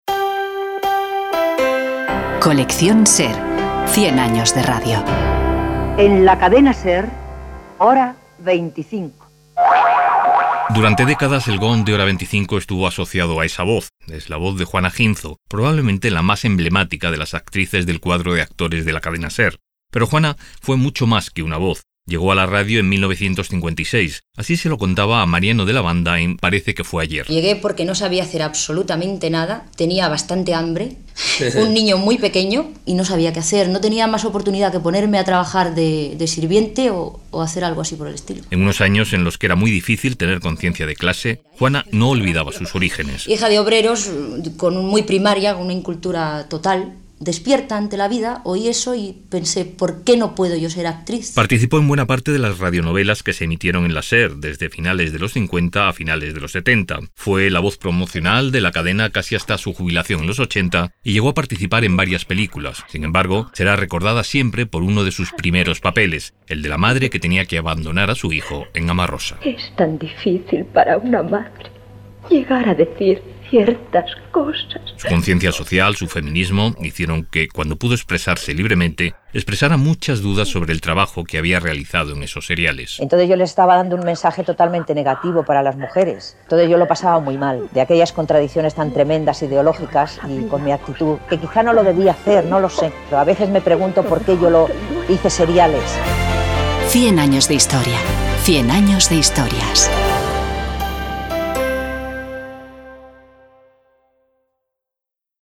Durante décadas el gong de Hora 25 estuvo asociado a la voz de Juana Ginzo, probablemente la más emblemática de las actrices del cuadro de actores de la Cadena SER.